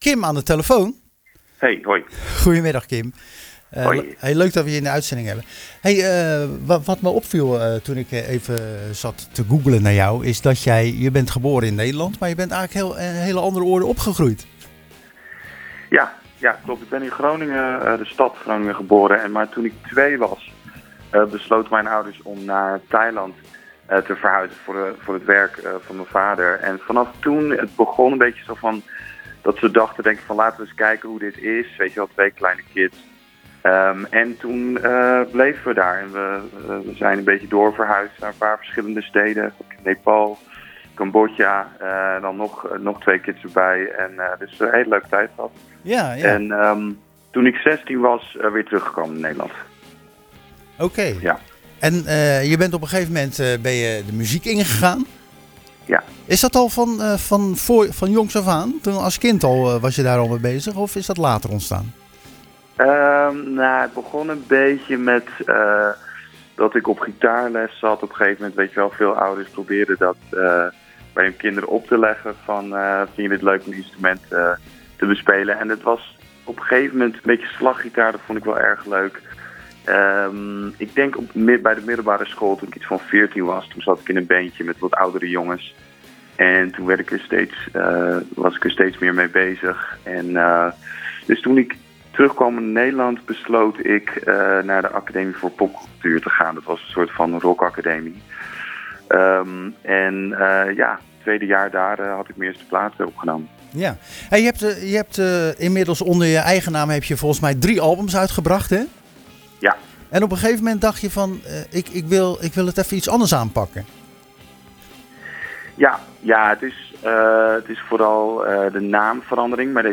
Tijdens de wekelijkse uitzending van Zwaardvis